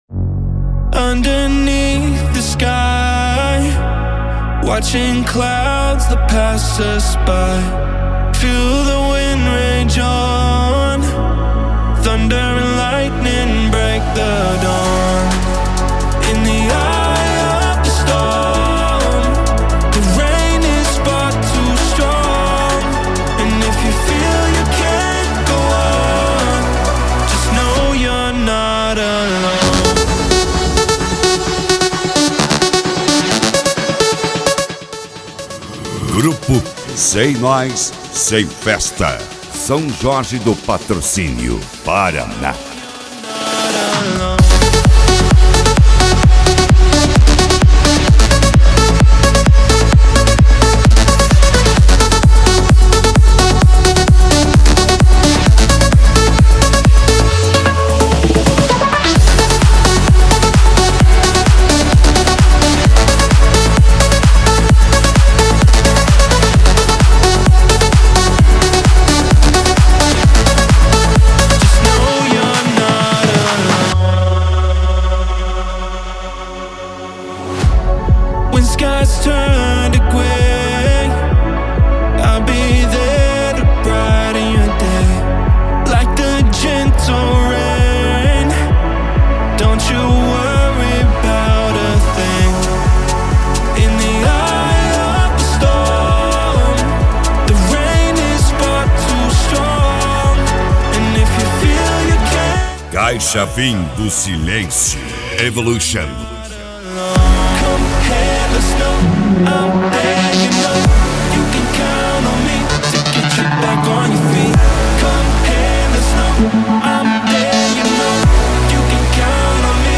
Eletronica
Funk
Funk Nejo
Mega Funk